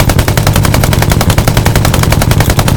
gun-turret-mid-2.ogg